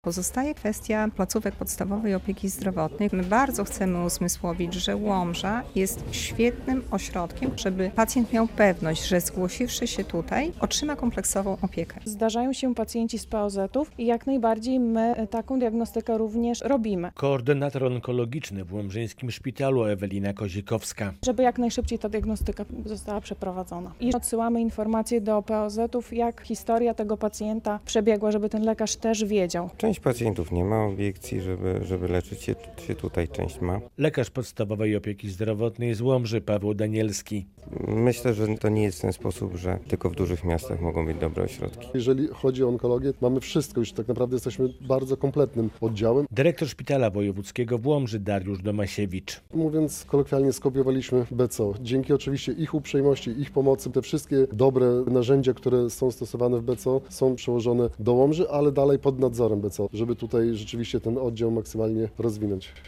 Czas zdiagnozowania nowotworu i rozpoczęcia leczenia jest tu najważniejszy, a w Łomży może być on znacznie krótszy niż w dużych ośrodkach specjalistycznych - przekonywali uczestnicy konferencji w Szpitalu Wojewódzkim w Łomży.